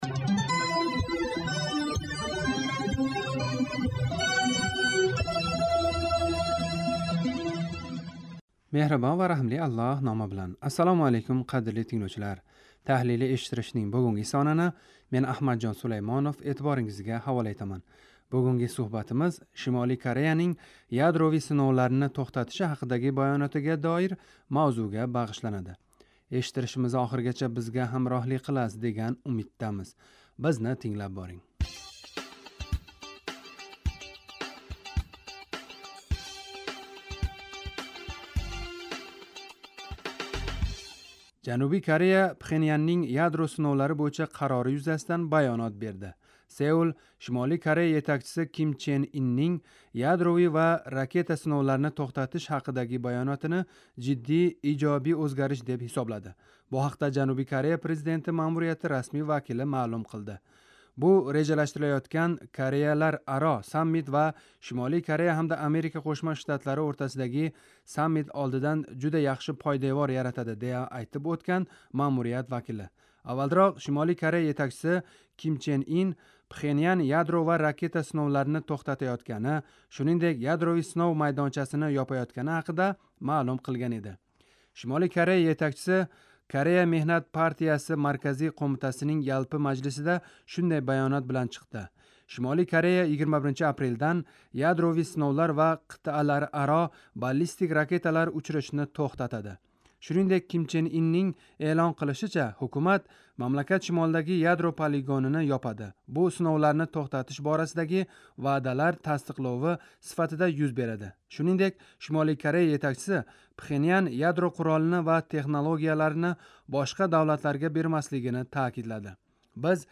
Таҳлилий эшиттиришнинг бугунги сонини эътиборингизга ҳавола этаман.